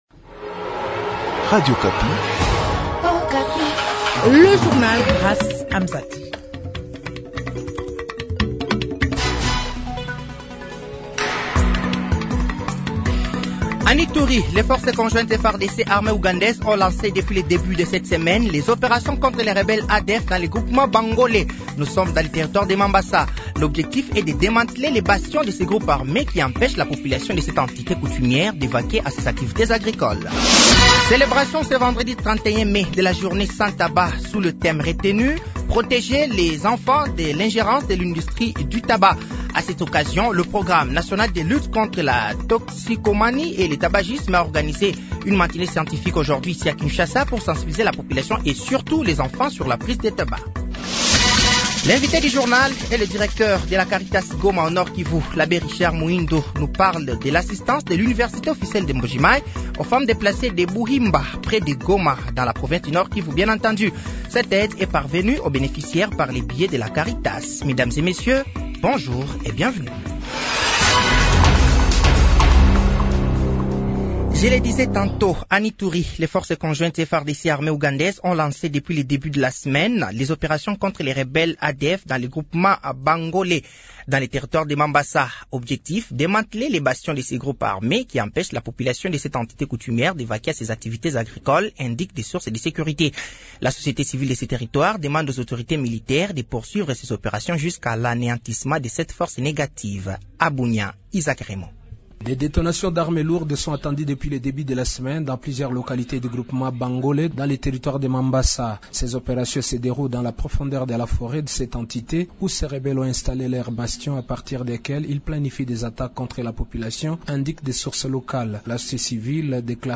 Journal français de 15h de ce vendredi 31 mai 2024